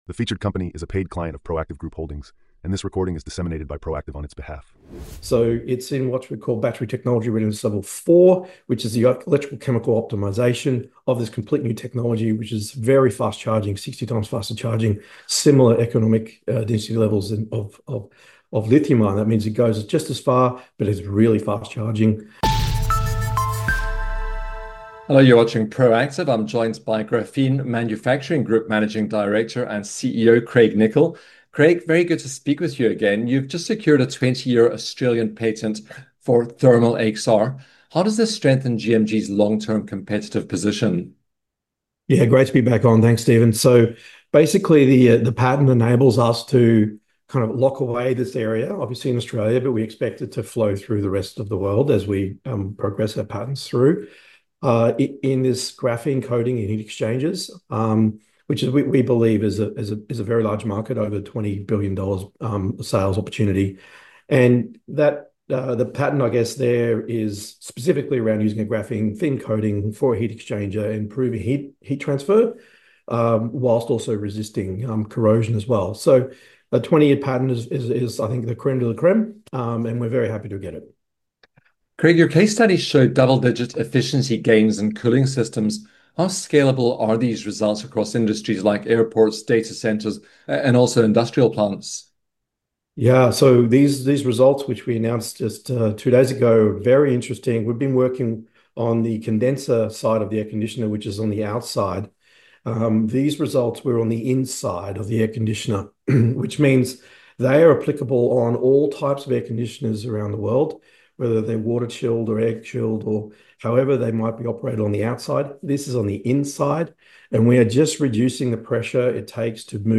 That Metal Interview Podcast